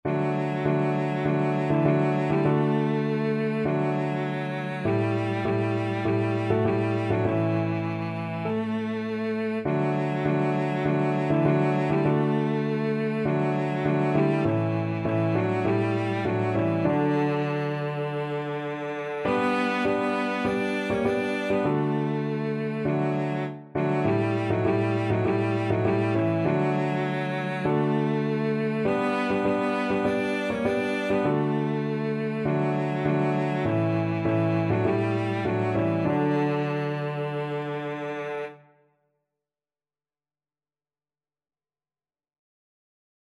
Cello version
Christian